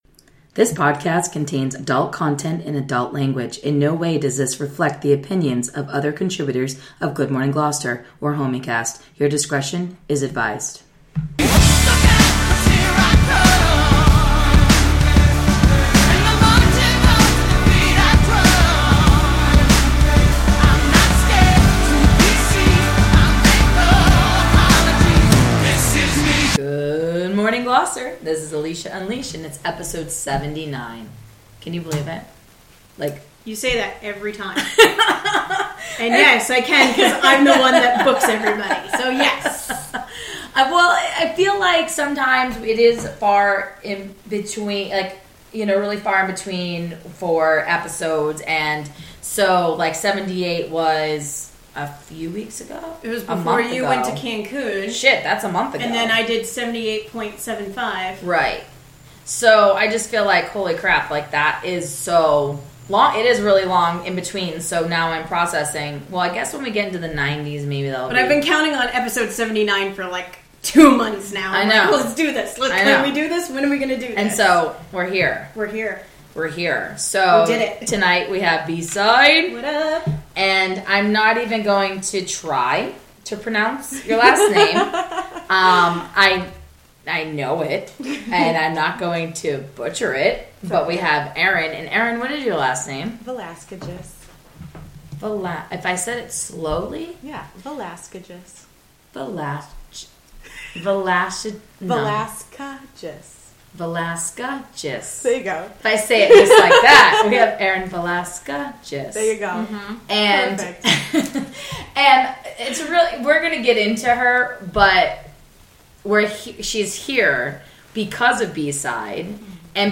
***Warning Minute 24 IT GETS LOOOOOOOOOOOUD